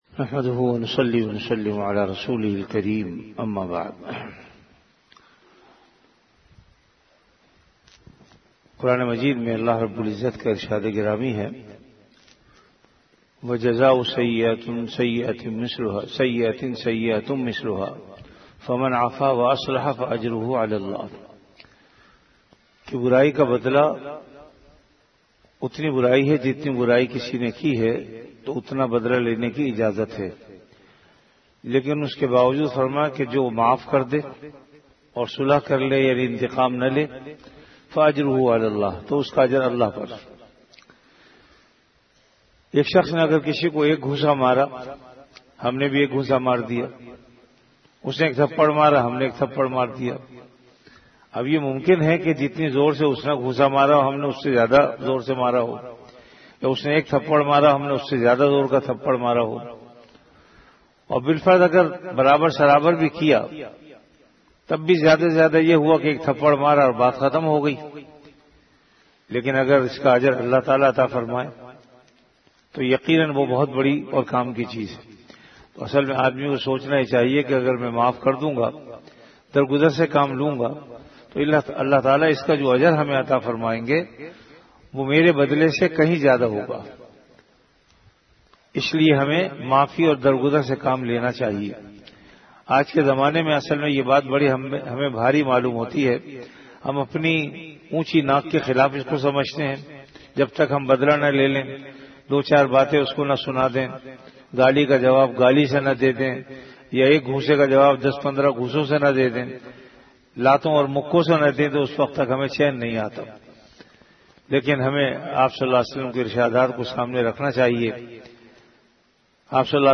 Delivered at Jamia Masjid Bait-ul-Mukkaram, Karachi.
Ramadan - Dars-e-Hadees · Jamia Masjid Bait-ul-Mukkaram, Karachi